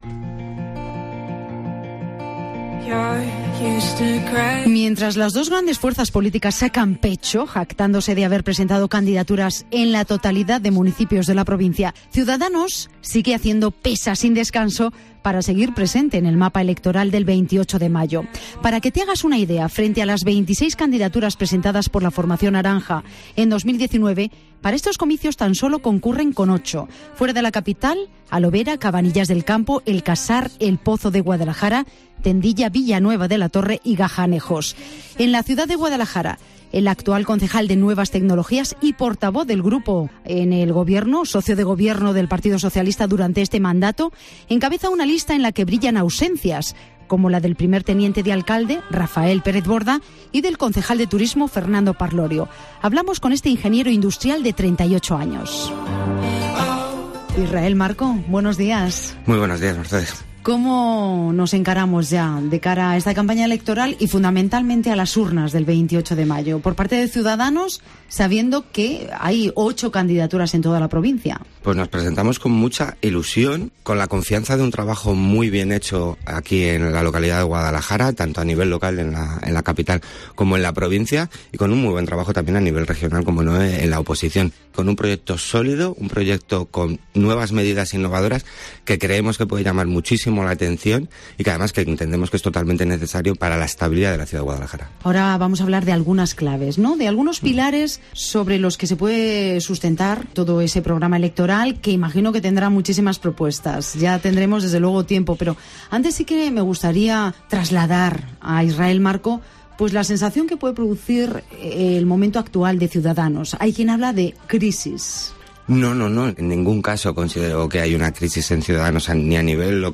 El concejal de Nuevas Tecnologías, Gestión Energética, Transparencia y Consejos de Barrio del Ayuntamiento de Guadalajara, Israel Marco, ha pasado por los micrófonos de 'Herrera en COPE Guadalajara' como candidato de Ciudadanos a la Alcaldía de la capital en las elecciones locales y autonómicas que se celebrarán el 28 de mayo.